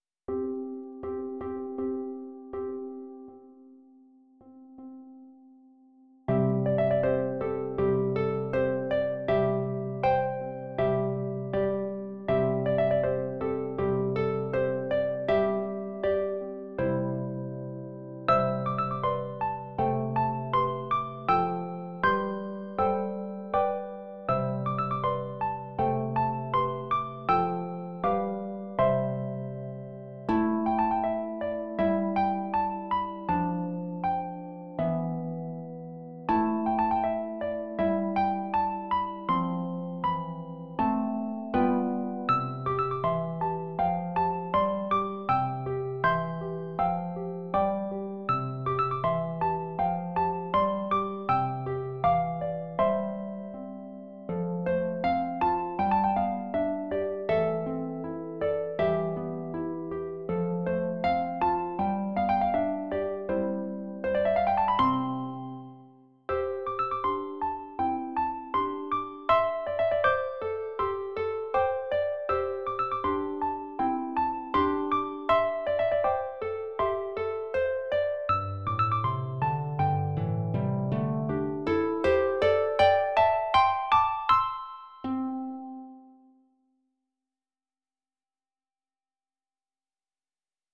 for two lever or pedal harps